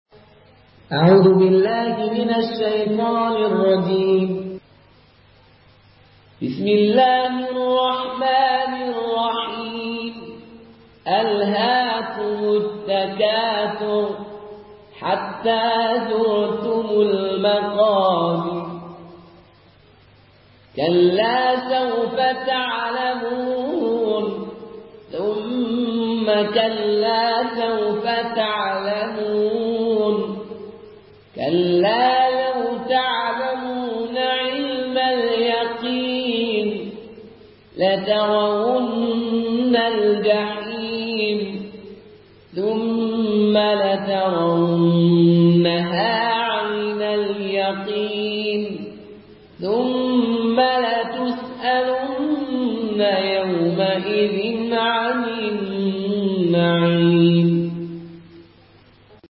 مرتل قالون عن نافع